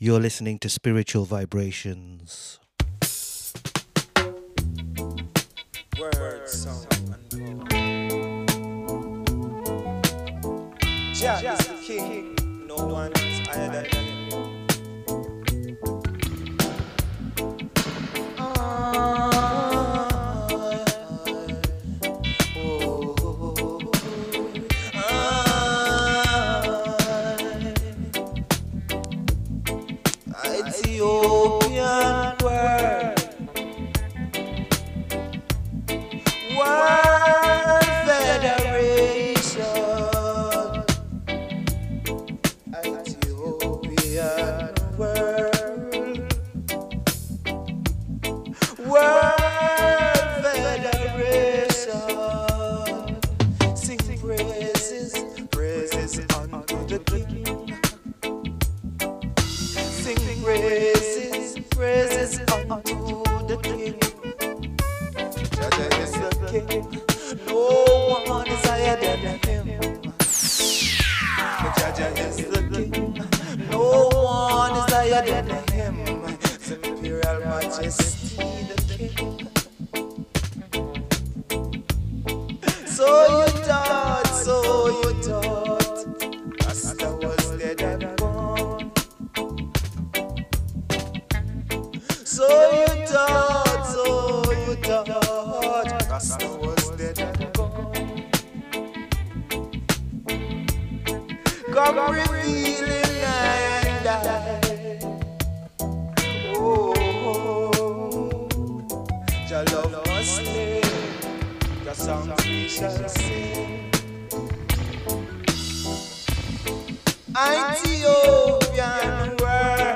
Broadcast live